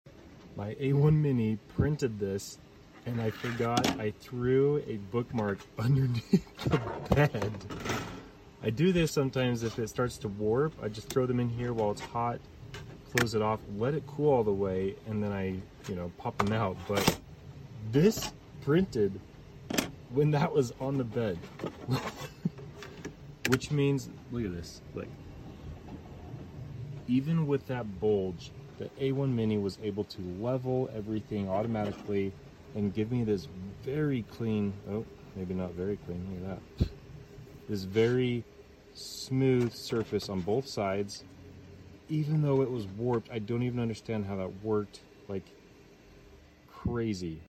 The Bambu Lab A1 Mini bed leveling is on fire!!